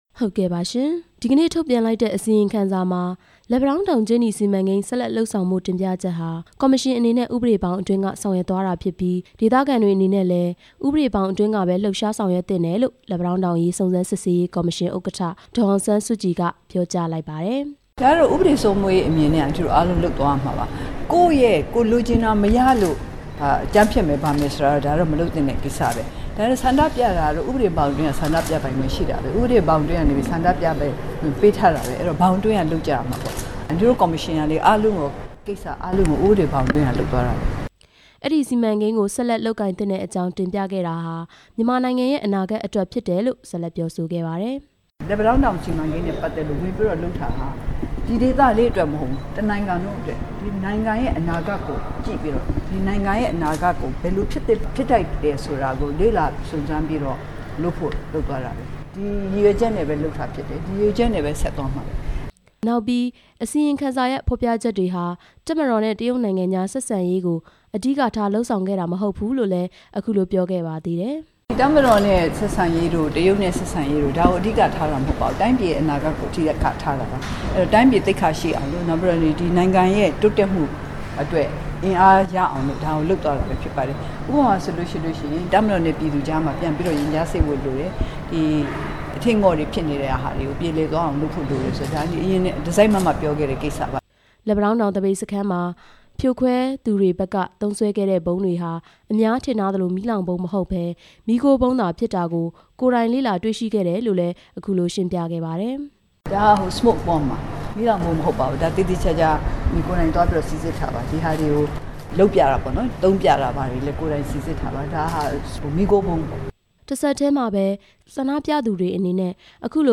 ဒီကနေ့ ပြည်ထောင်စုလွှတ်တော် ခေတ္တရပ်နားချိန်မှာ စုံစမ်းစစ်ဆေးကော်မရှင် အစီရင်ခံစာနဲ့ ပတ်သက်ပြီး သတင်းထောက်တွေရဲ့ မေးမြန်းချက်ကို ဖြေကြားရာမှာ အဲဒီလို ထည့်သွင်းပြောခဲ့တာပါ။